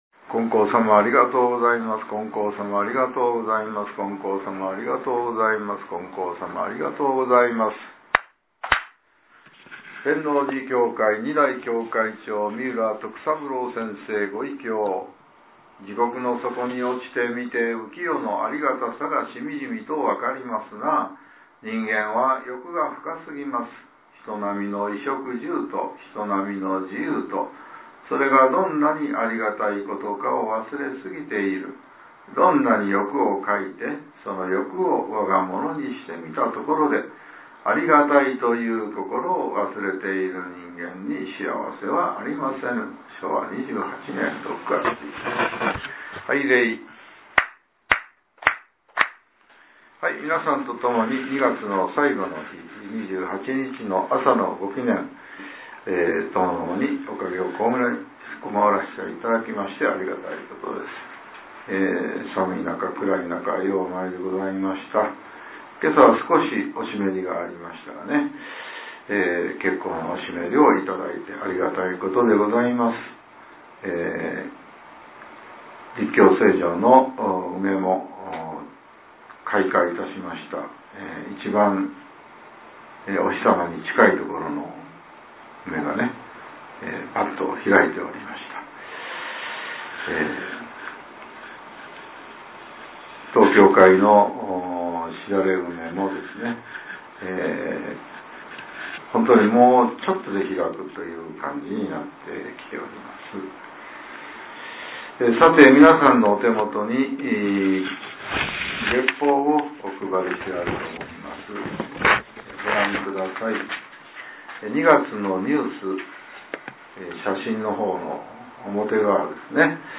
令和７年３月２０日（朝）のお話が、音声ブログとして更新されています。